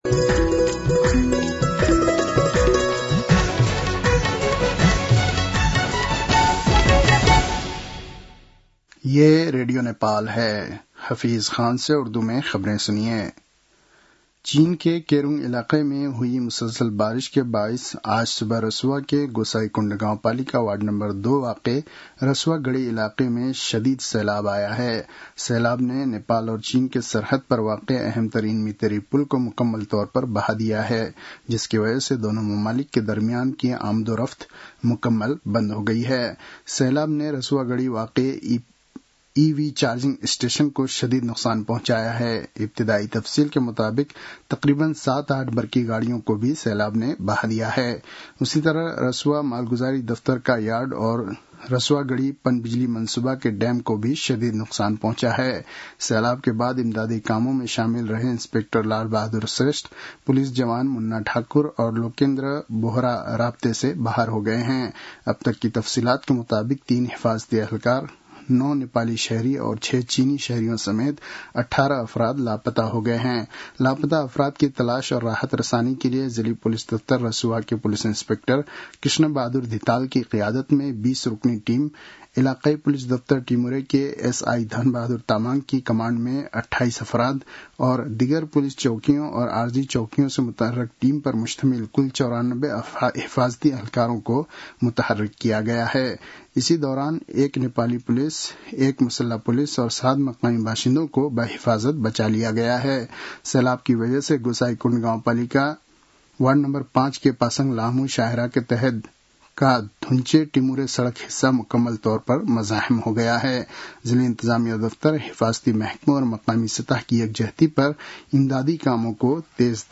उर्दु भाषामा समाचार : २४ असार , २०८२